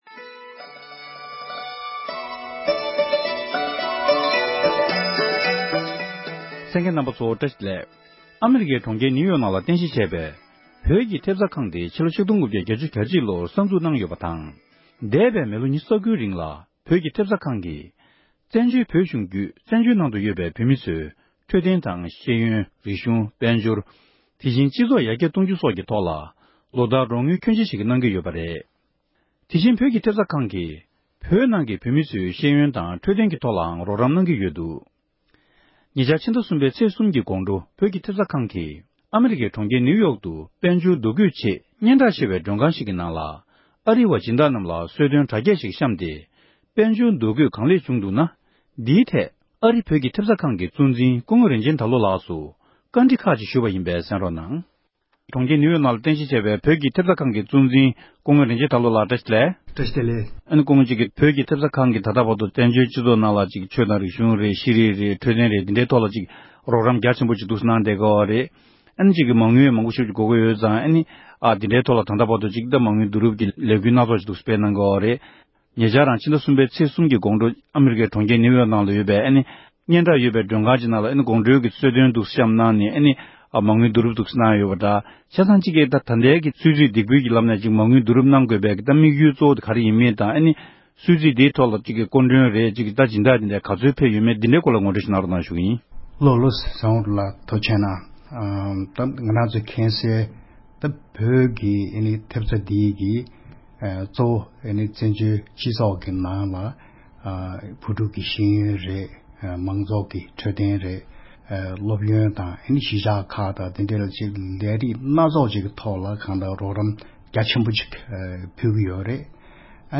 གནས་བཀའ་དྲི་ཞུས་པ་ཞིག་གསན་རོགས༎